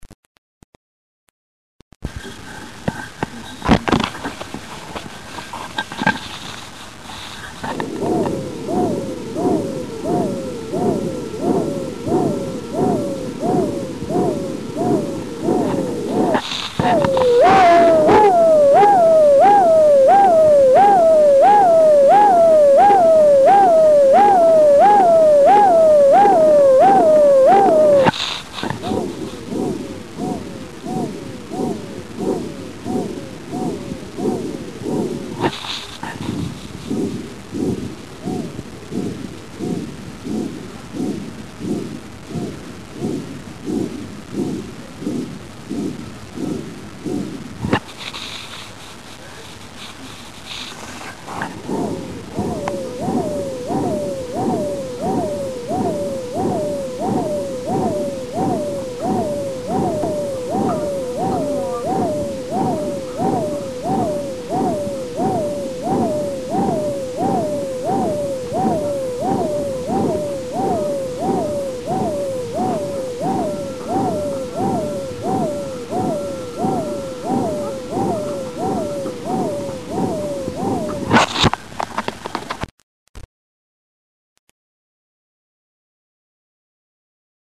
Shuntgeluiden
Fluittoon
Nummer-6-Fluittoon.mp3